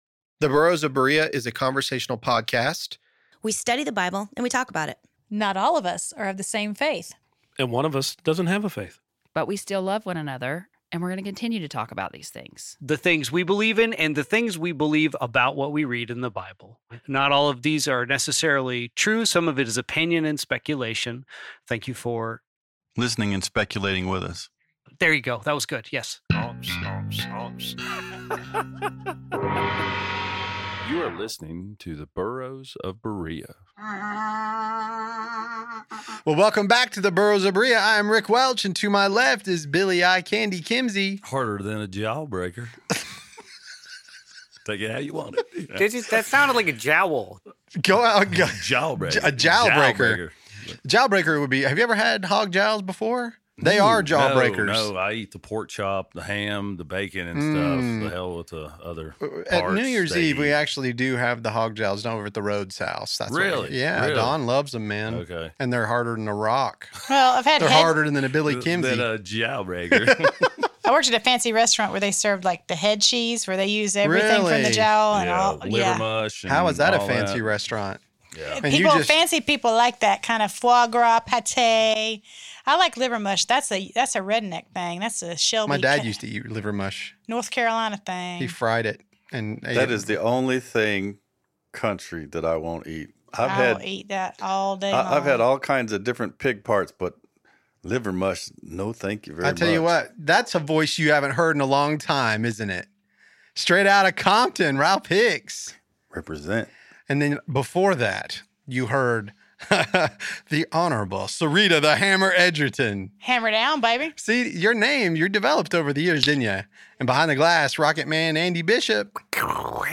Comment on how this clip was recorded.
Well... the time has come for The Burros of Berea Podcast studio sessions to come to an end.